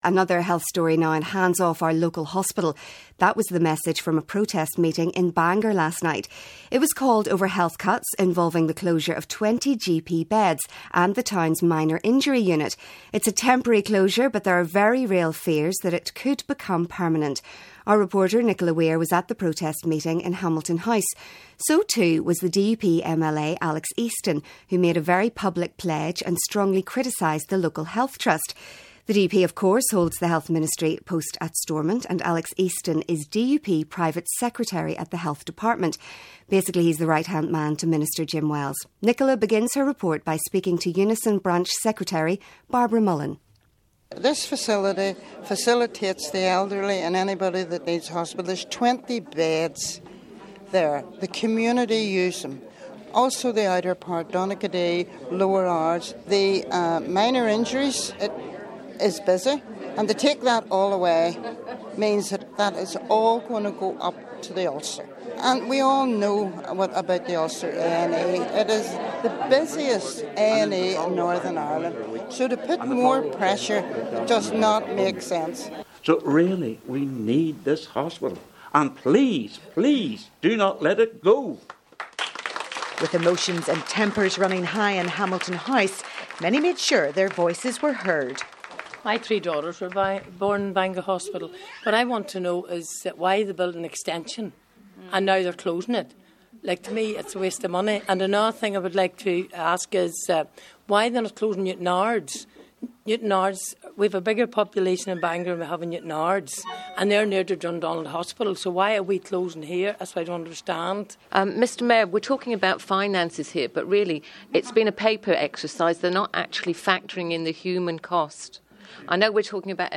That was the message from a protest meeting in Bangor last night. It was called over health cuts involving the closure of 20 GP beds and the town's minor injury unit.